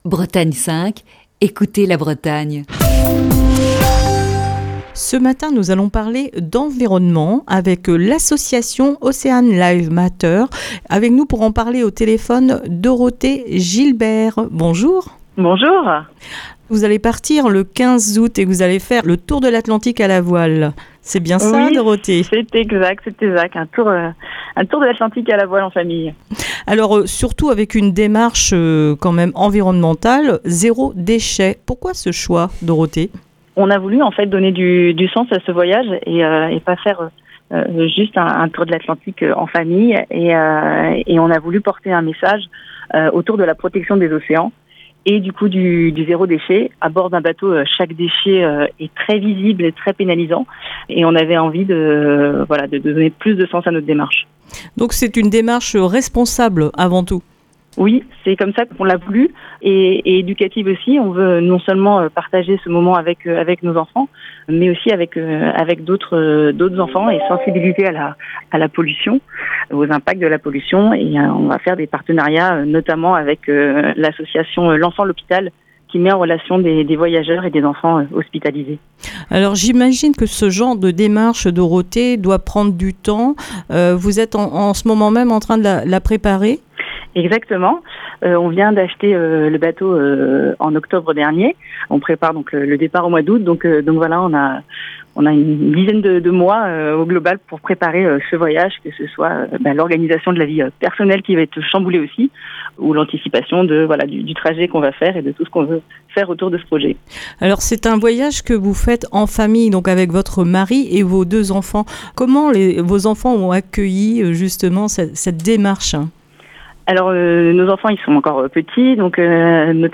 Émission du 5 février 2021.